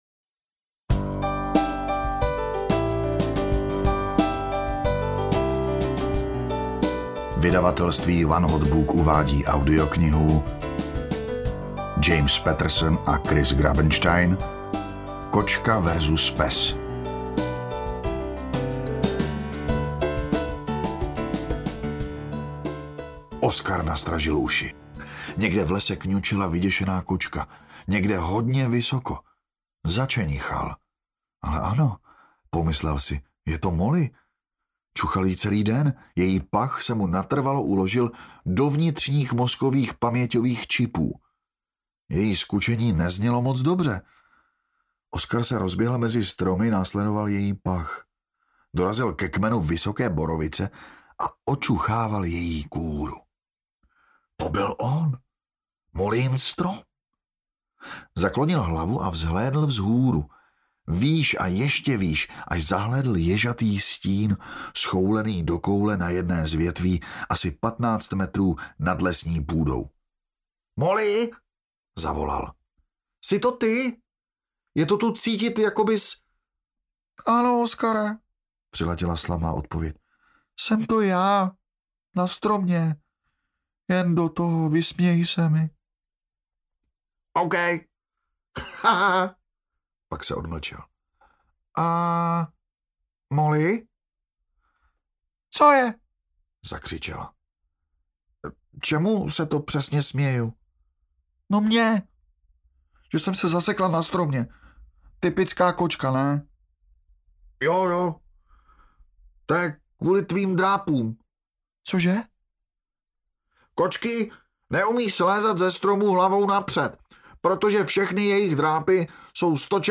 Kočka vs. Pes audiokniha
Ukázka z knihy